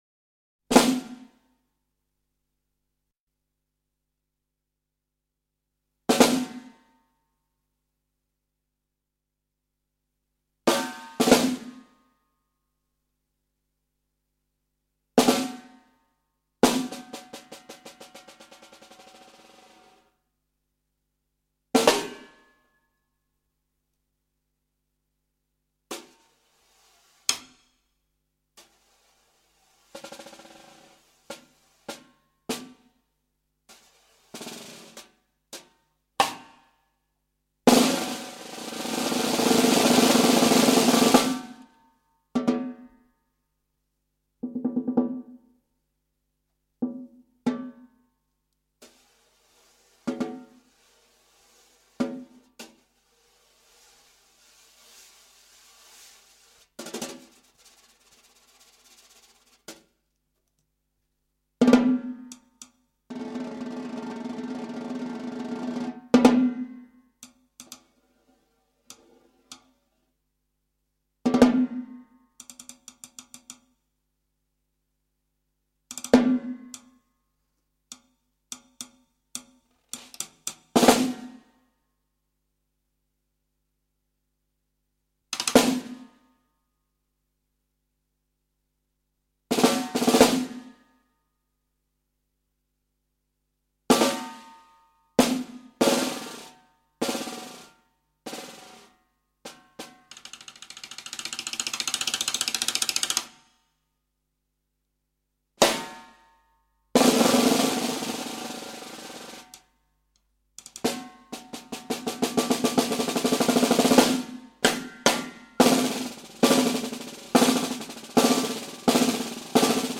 Genre: Snare Drum
Solo Snare Drum